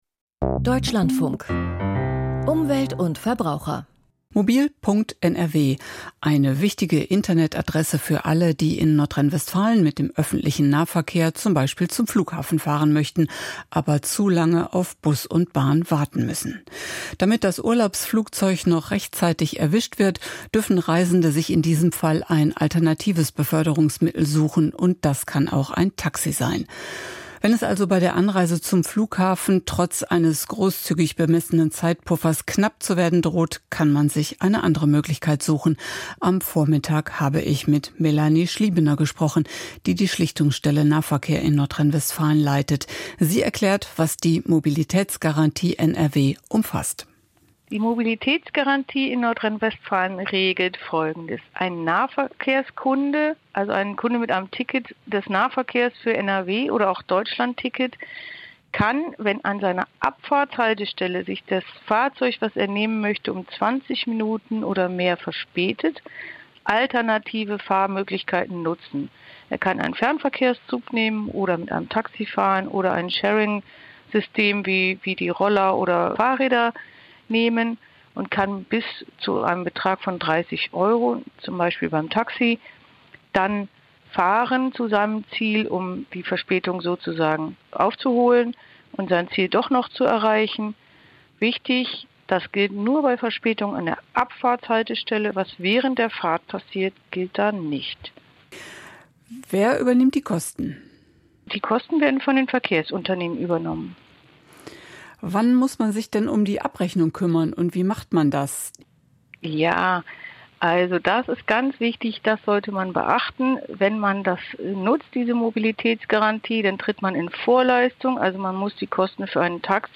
Mobilitätsgarantie NRW, Int.